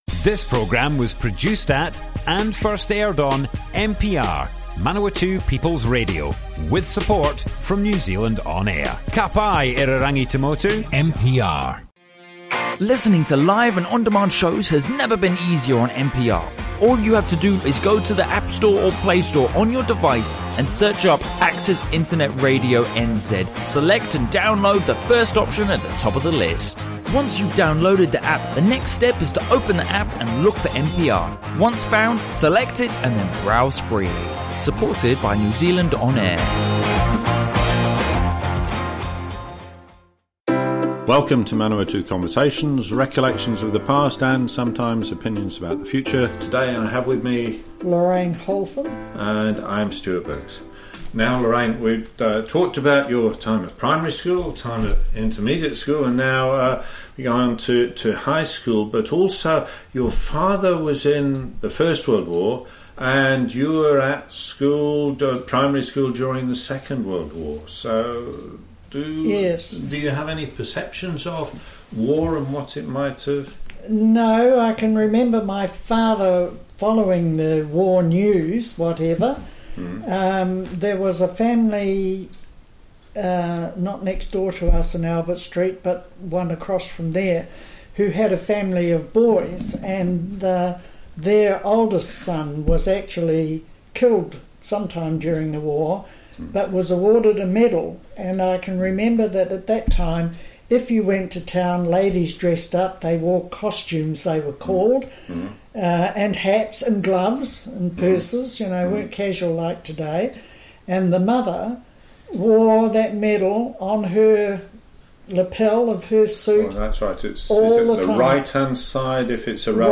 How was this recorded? Manawatu Conversations More Info → Description Broadcast on Manawatu People's Radio 12th March 2019.